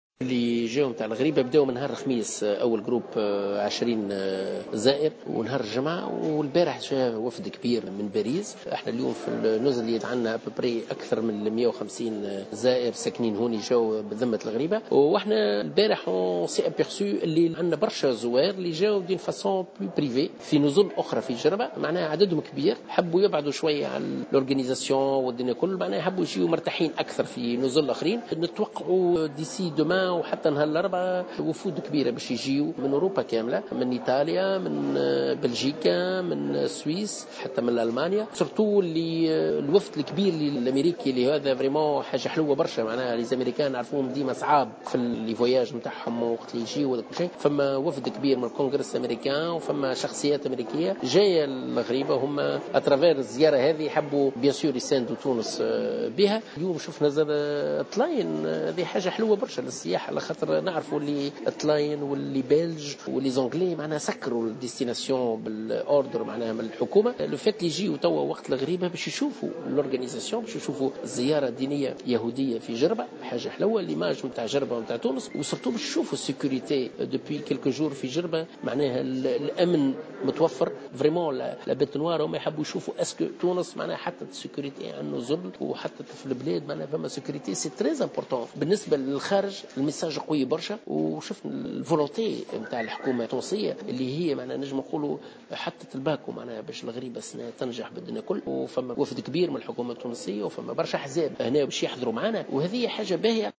أكد روني الطرابلسي، منظم رحلات معبد الغريبة بجزيرة جربة في تصريح لمراسلة "الجوهرة أف أم" تواصل توافد اليهود على معبد الغريبة للمشاركة في الزيارة السنوية التي ستنتظم يومي 25 و26 ماي 2016.